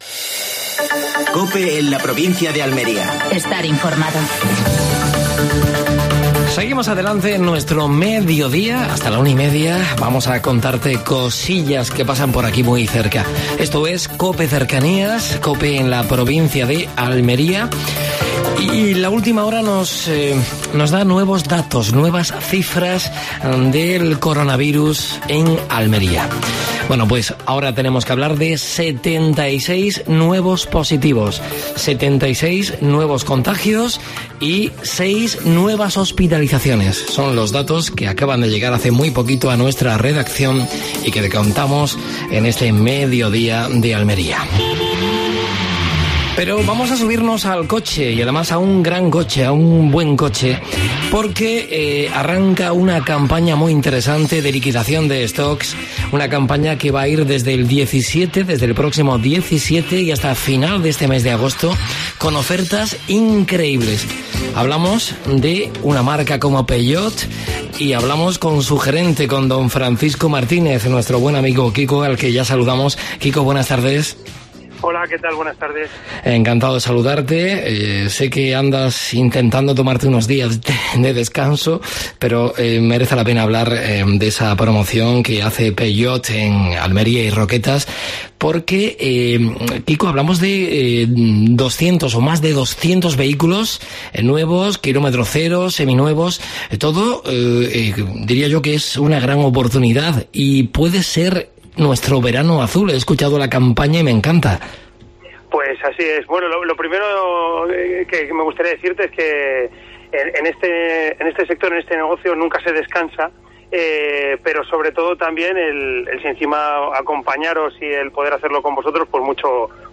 AUDIO: Actualidad en Almería. Datos actualizados sobre el coronavirus en la provincia. Entrevista